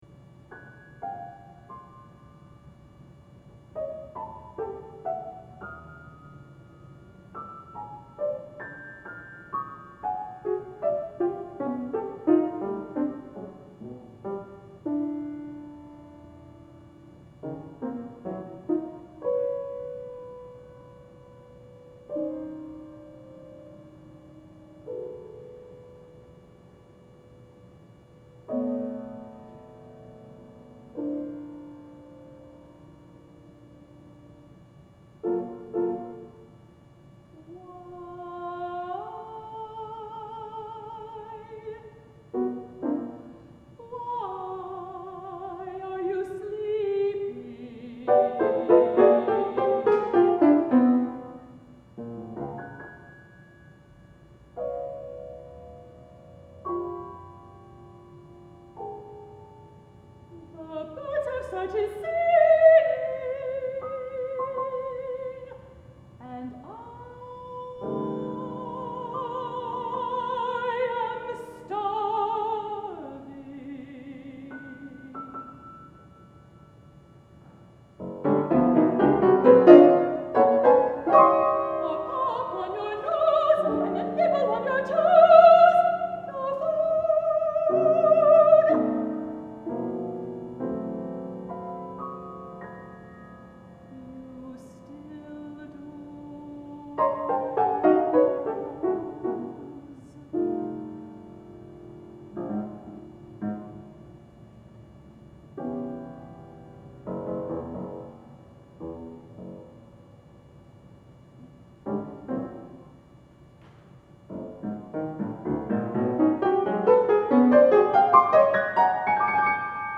for mezzo soprano and piano